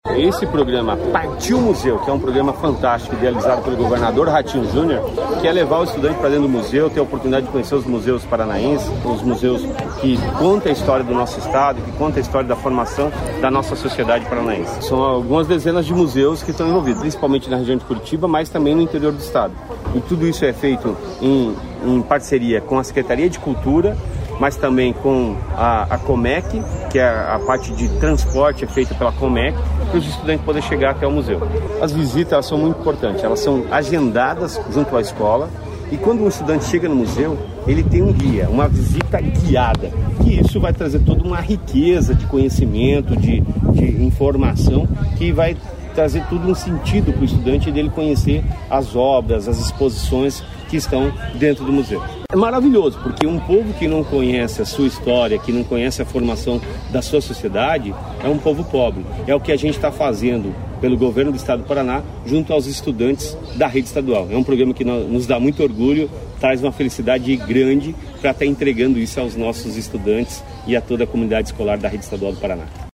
Sonora do secretário da Educação, Roni Miranda, sobre o programa Partiu Museu